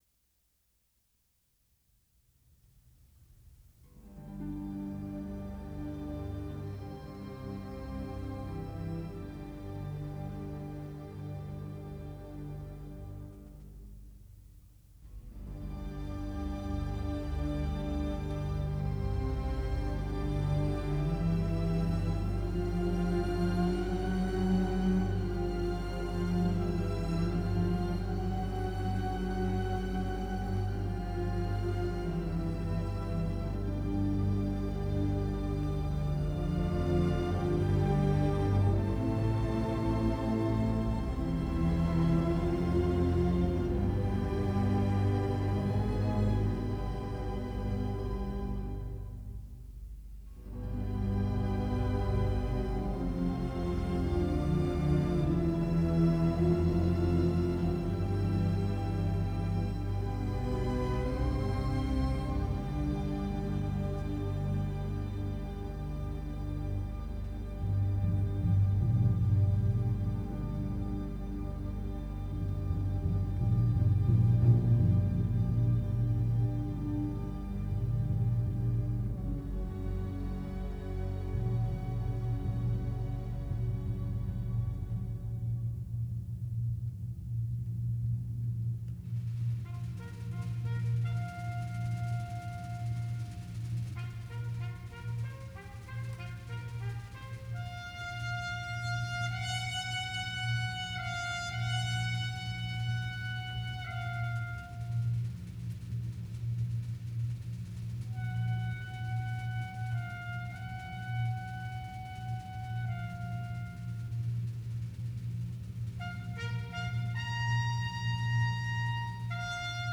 Recorded At – City Auditorium, Houston
Transferred from a 15ips tape